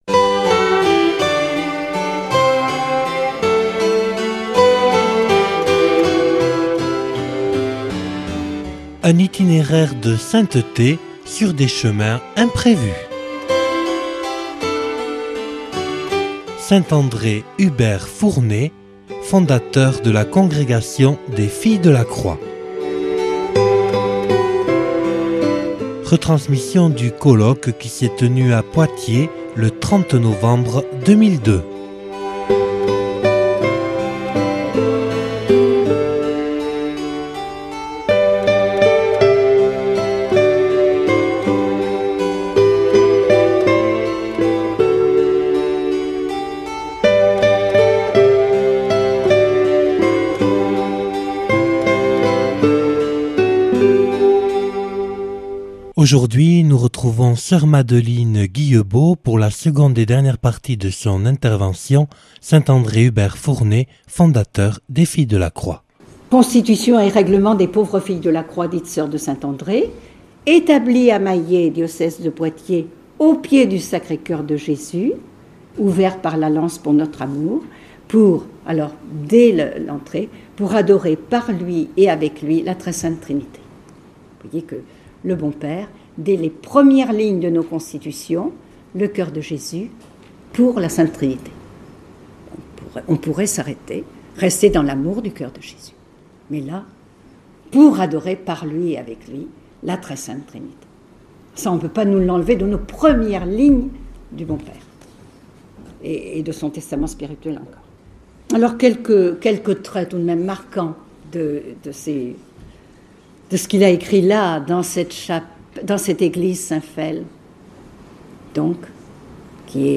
(Colloque enregistré le 30/11/2002 à Poitiers).